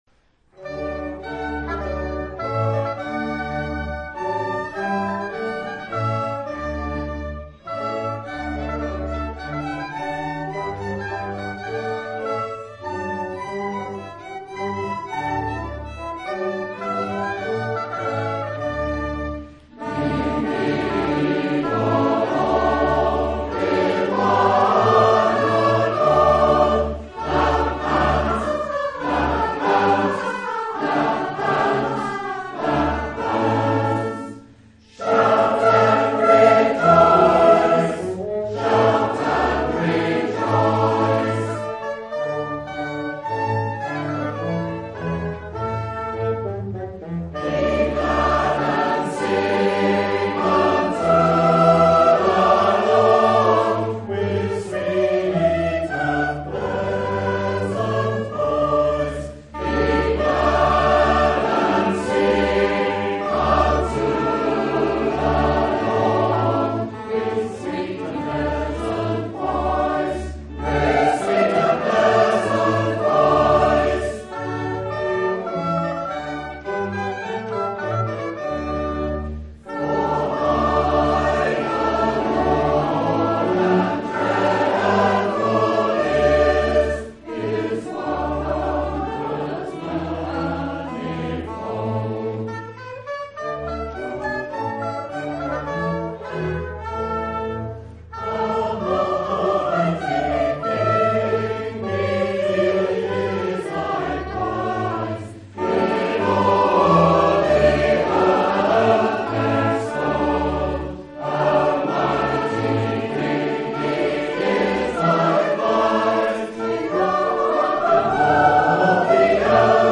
West Gallery Music
As the century progressed, a variety of instruments were brought in to accompany the singers so that by the end of the century, the choir consisted of singers and a small orchestra; Thomas Hardy referred to the group as a 'quire'.
The instruments included flute, oboe, clarinet, bassoon, violin and cello (often referred to as a bass viol) and possibly even a serpent.